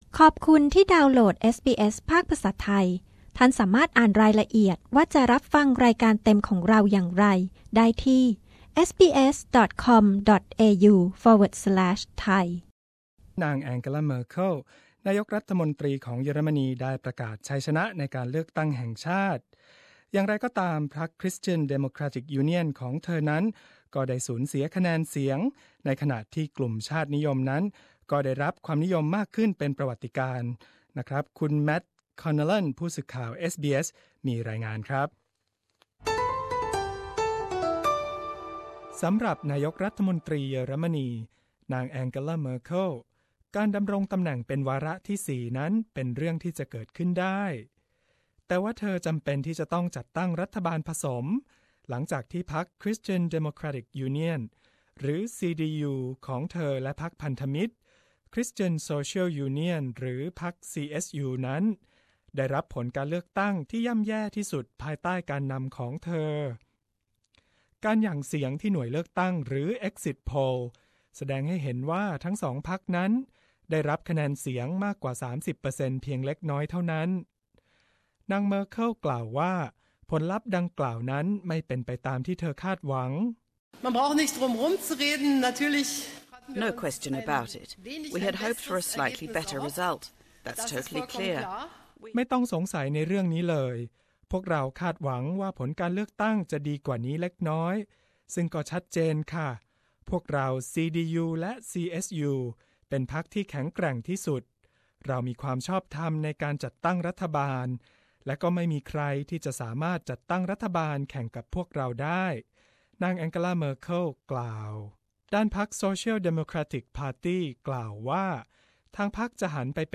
ฟังรายงานผลการเลือกตั้งเยอรมนี ซึ่งพรรคชาตินิยม AFD จะเข้าสู่สภาฯ เป็นครั้งแรก ในขณะที่นางแองเกลา เมอร์เคิลคะแนนเสียงตกต่ำและต้องรีบจัดตั้งรัฐบาลผสม